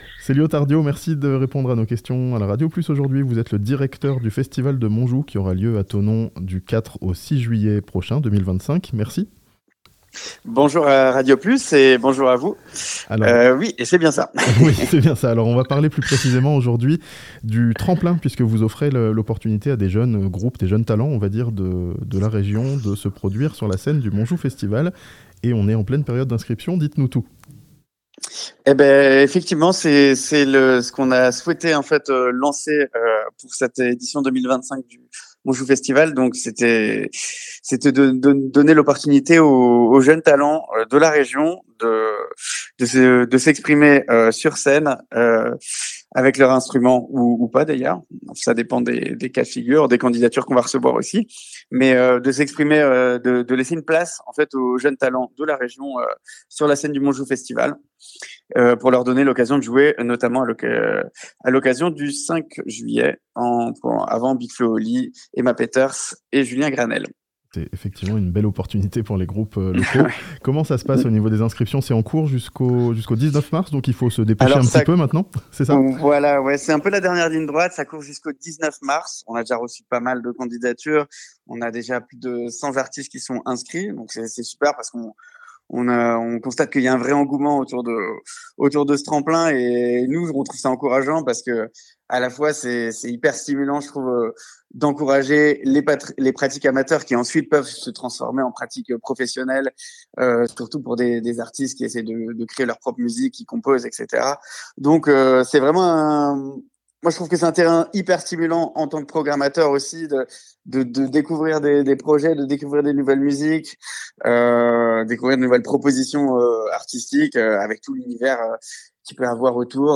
Un tremplin pour jouer au Montjoux Festival en première partie de Big Flo & Oli (interview)